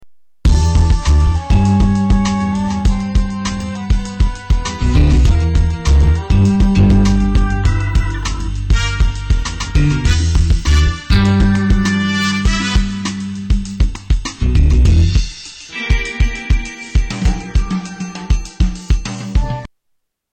Tags: Sound effects Espionage music Espionage Stealth Music